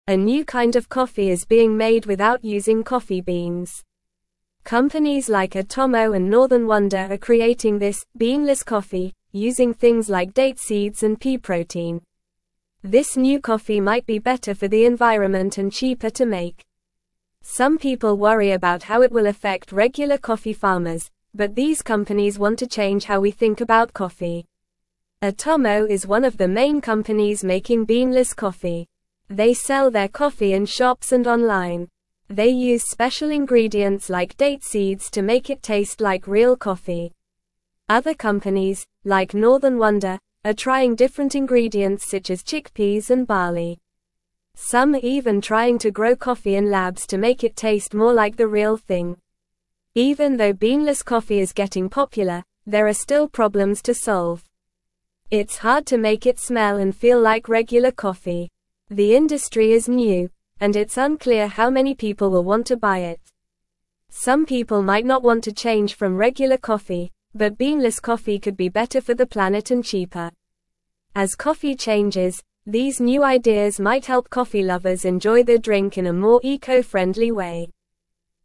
Normal
English-Newsroom-Lower-Intermediate-NORMAL-Reading-Beanless-Coffee-A-New-Kind-of-Earth-Friendly-Brew.mp3